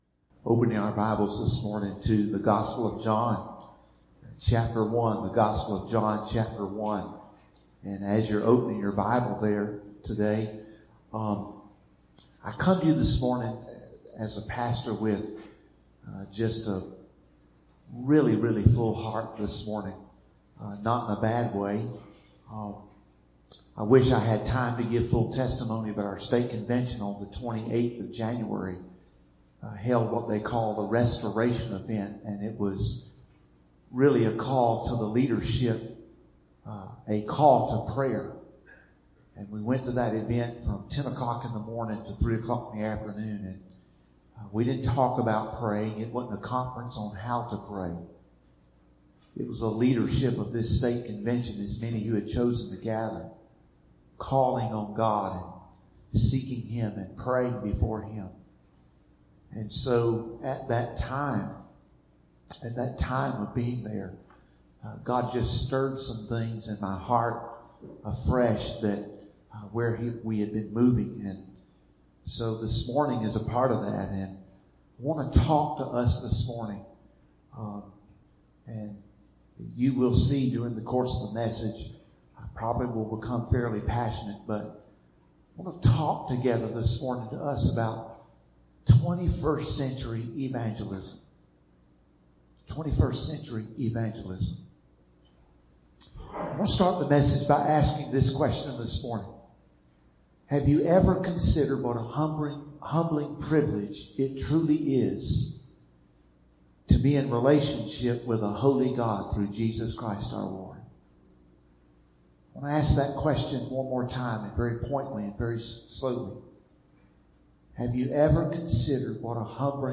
2-9-20-Website-Sermon-CD.mp3